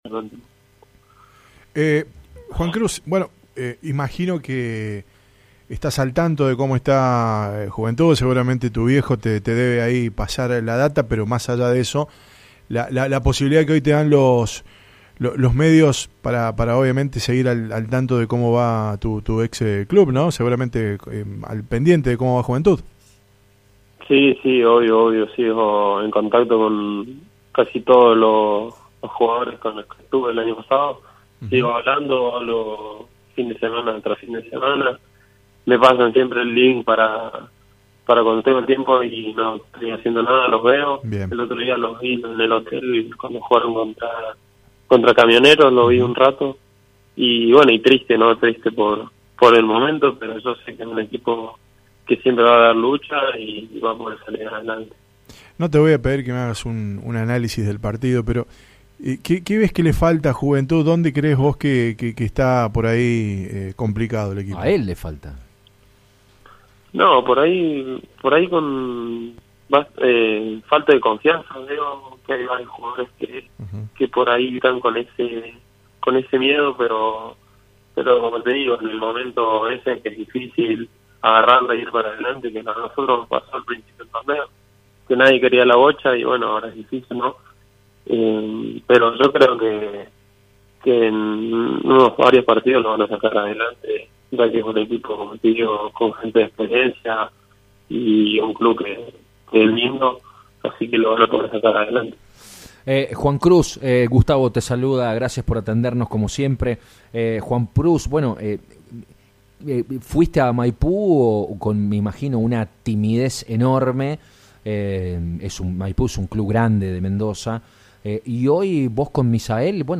En dialogo con Show Deportivo, programa que se emite de lunes a viernes de 20 a 21 en FM Radio La Bomba,